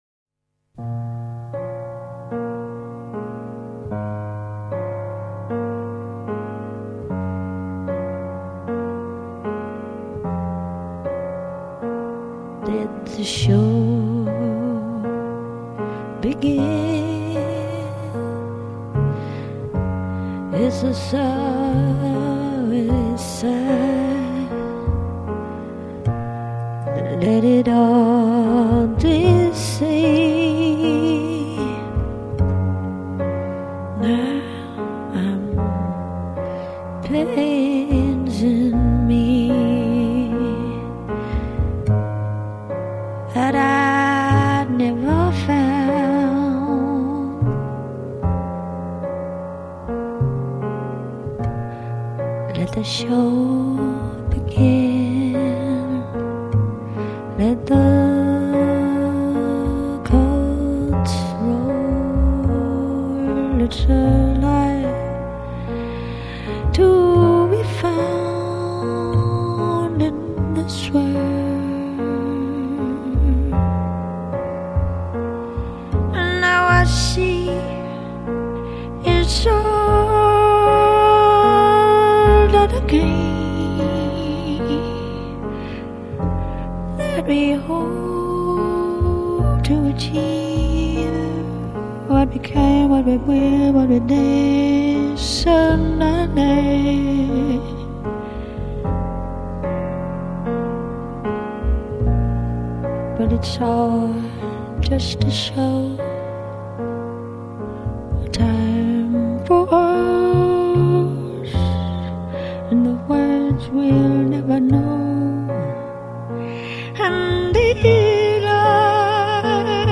Её хриплый голос узнают с первых нот...